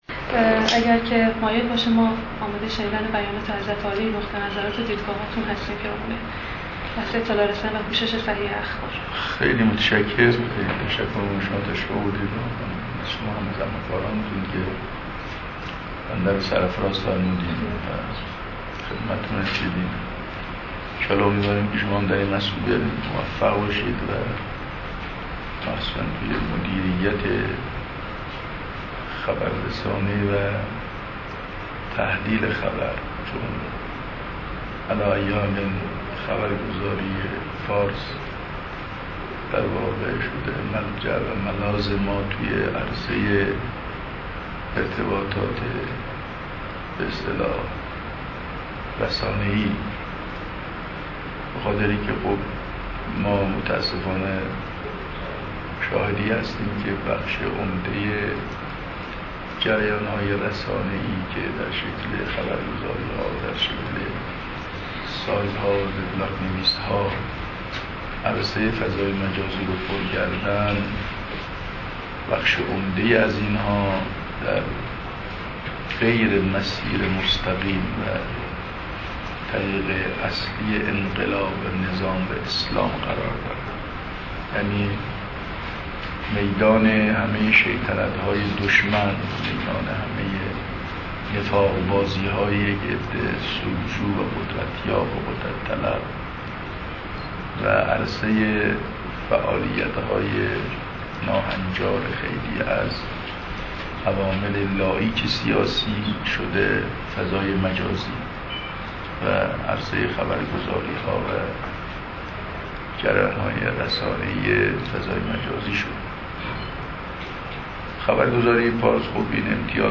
مصاحبه با خبرگزاری فارس